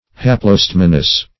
Search Result for " haplostemonous" : The Collaborative International Dictionary of English v.0.48: Haplostemonous \Hap`lo*stem"o*nous\ (h[a^]p`l[-o]*st[e^]m"[-o]*n[u^]s), a. [Gr.